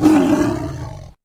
CosmicRageSounds / wav / general / combat / creatures / tiger / he / attack1.wav
attack1.wav